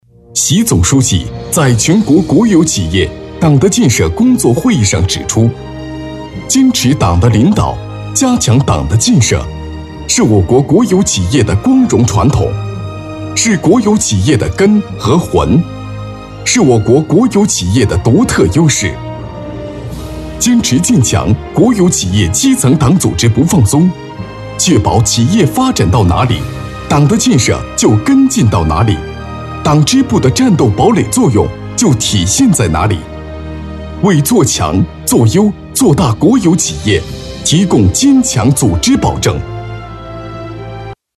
稳重磁性 企业专题,人物专题,医疗专题,学校专题,产品解说,警示教育,规划总结配音
大气磁性男音，声音偏老陈，稳重。